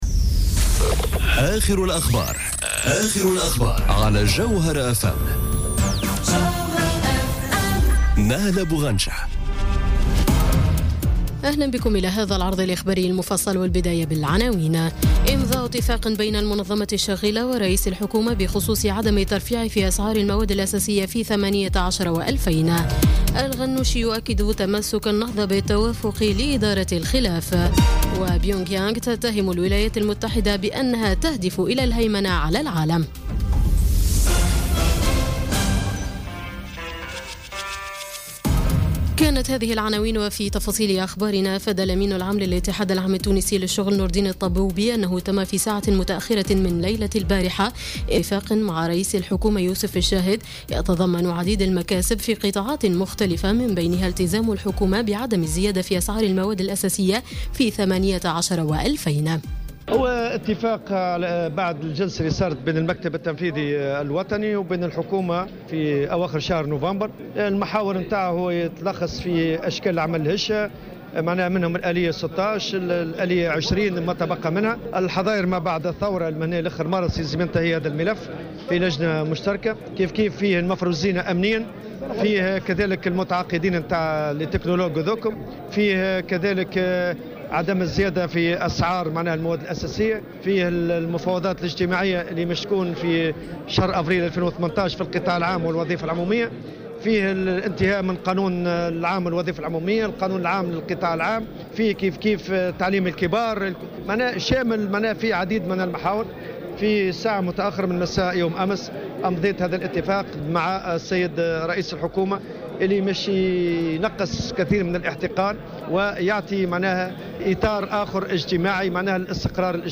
نشرة أخبار السابعة مساء ليوم السبت 23 ديسمبر 2017